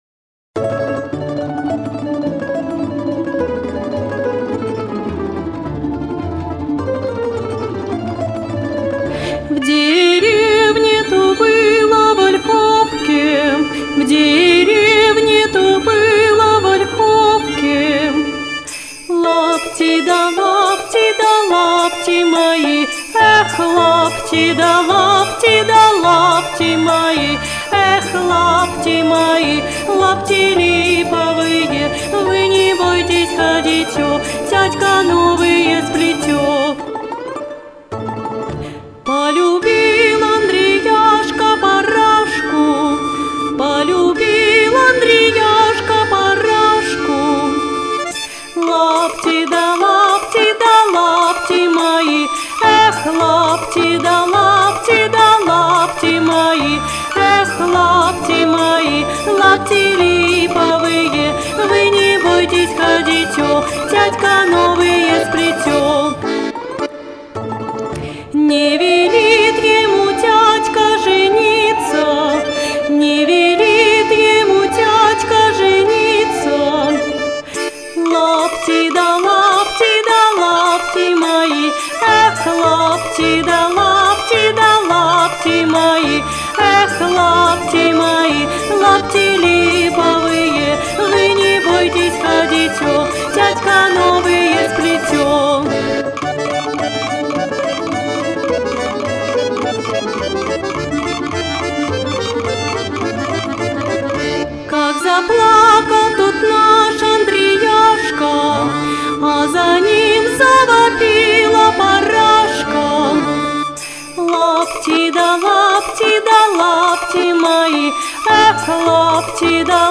• Качество: Хорошее
• Жанр: Детские песни
теги: деревня, народный мотив, минус